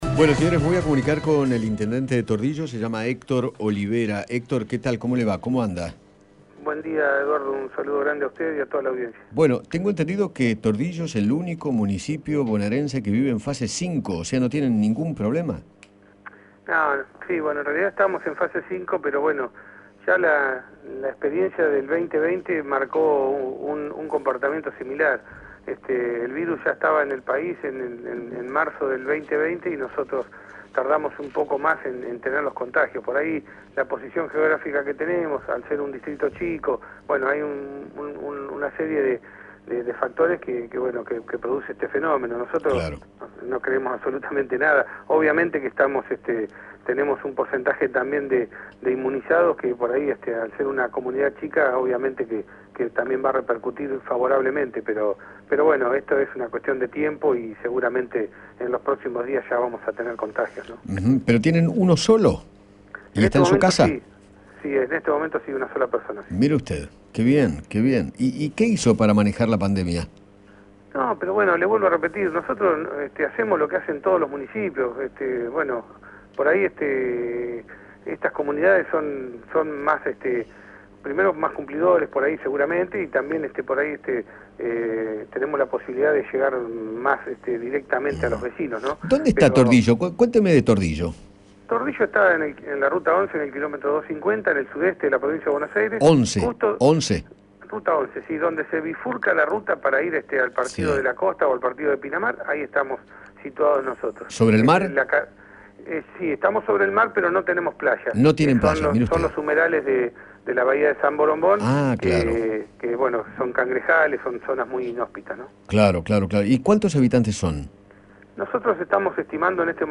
Eduardo Feinmann conversó con Héctor Olivera, intendente del único municipio en la Provicnia que se encuentra en fase 5, y explicó cómo hizo para lograrlo.